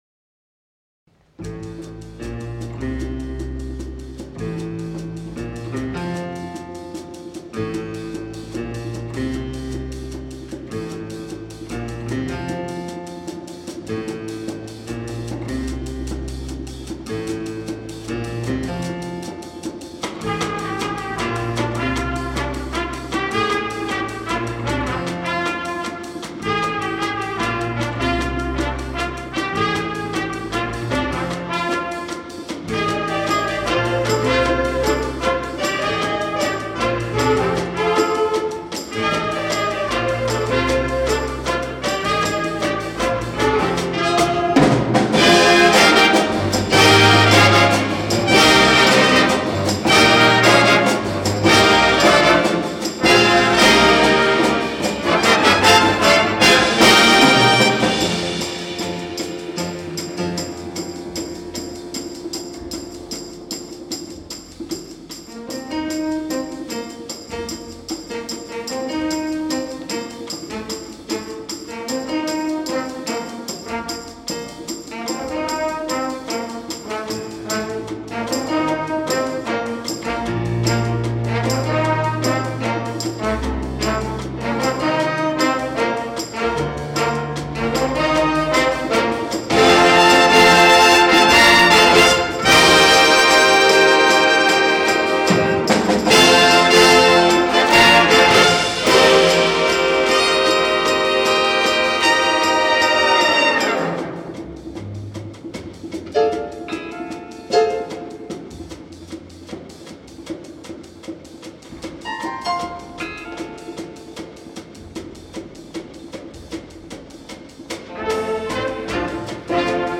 Jazz Concert 2025 Recordings